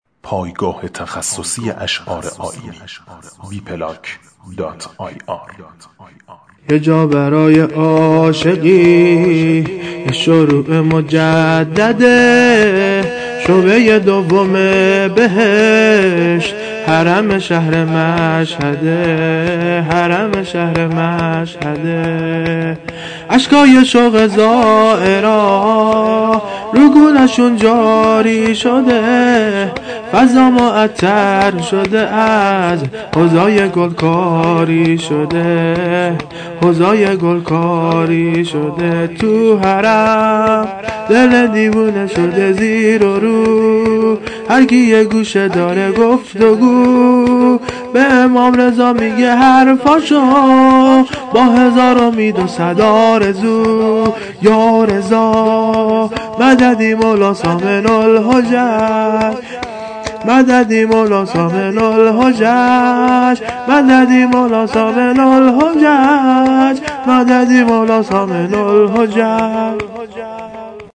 ولادت
شور